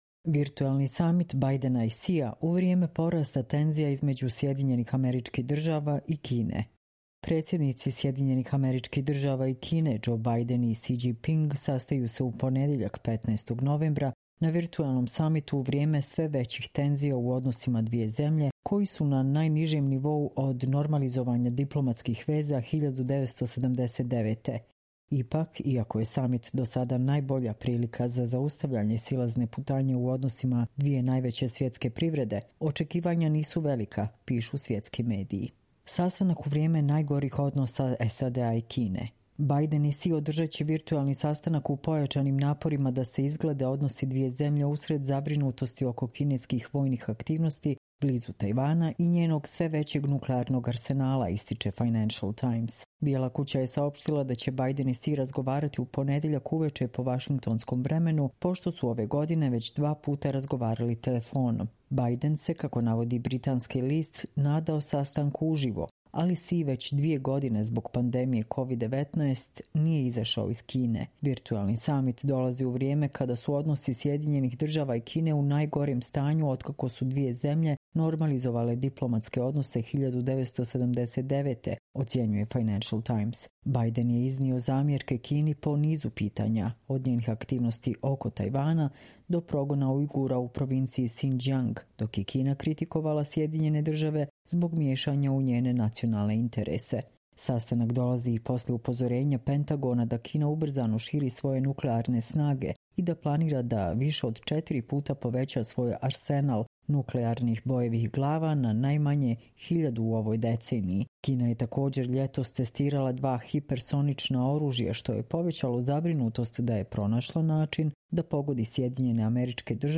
Čitamo vam: Virtuelni samit Bajdena i Sija u vreme porasta tenzija između SAD i Kine